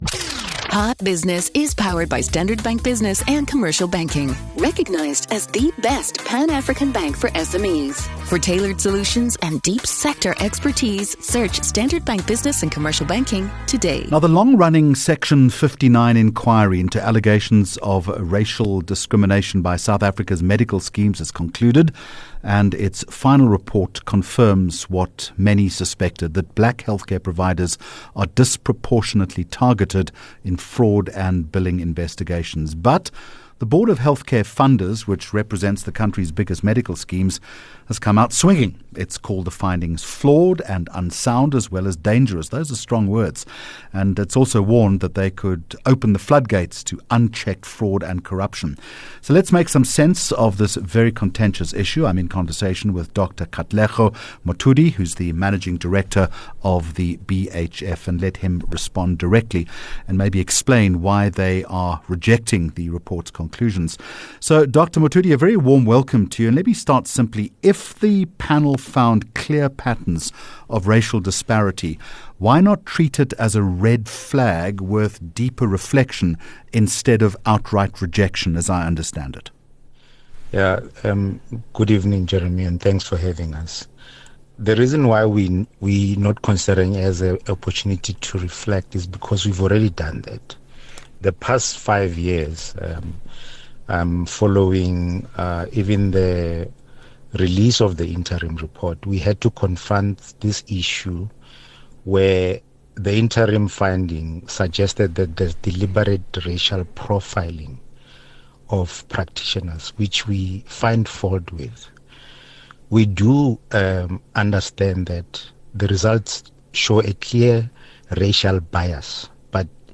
8 Jul Hot Business Interview